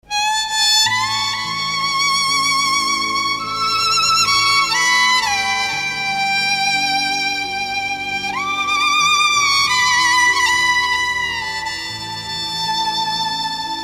nice-voilen_31332.mp3